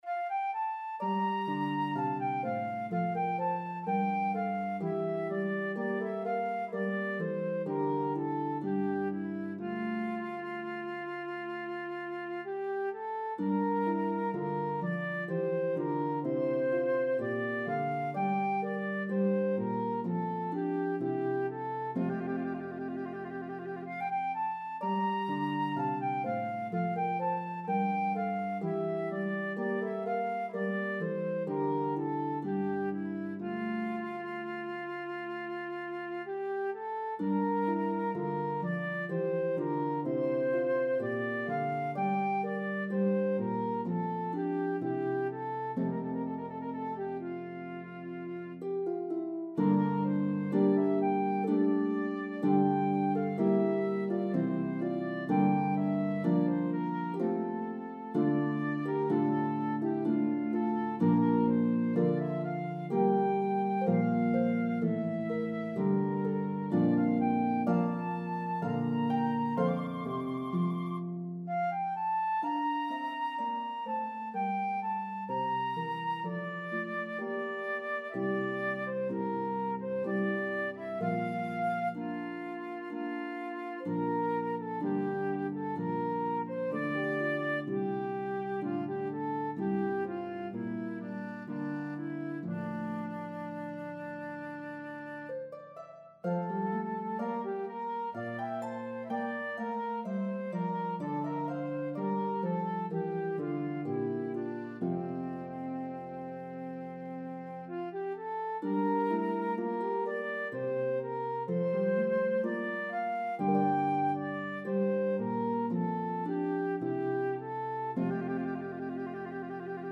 Italian Baroque style pieces
lovely slow air